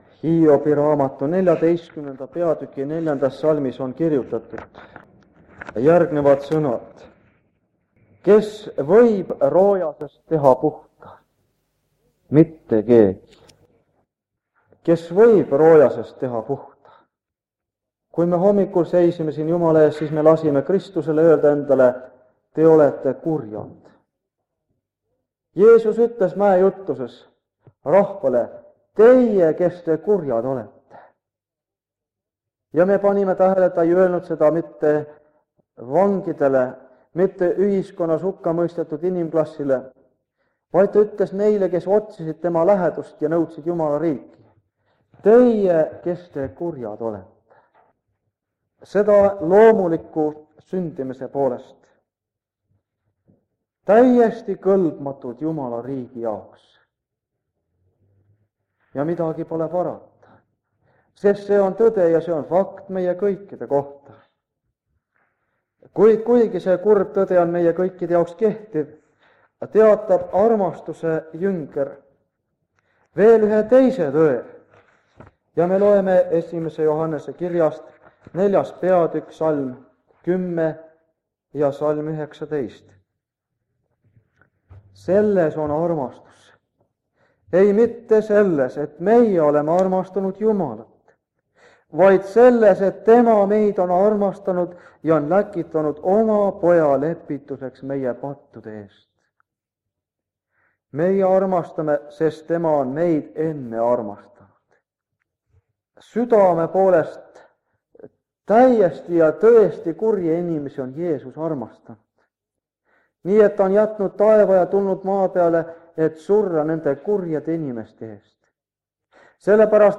Jutlused
Jutlus vanalt lintmaki lindilt 1977 aasta Kingissepa linnast Saaremaalt.